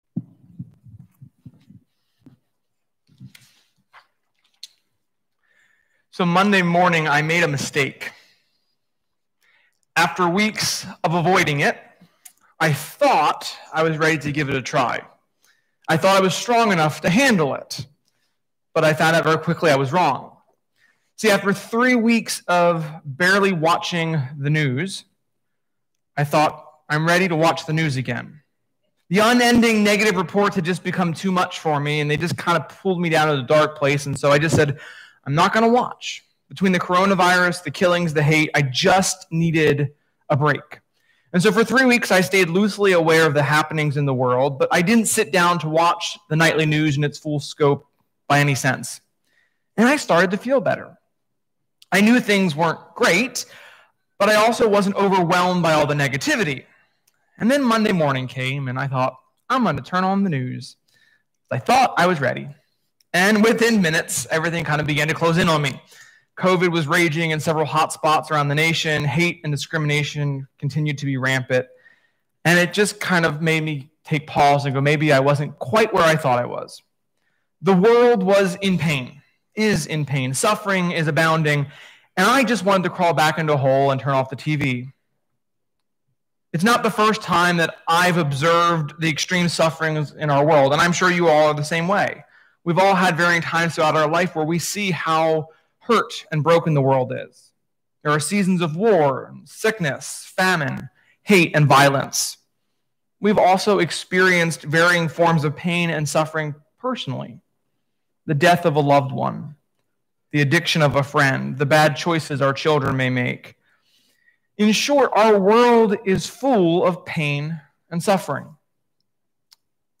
Sermon-7.19.20.mp3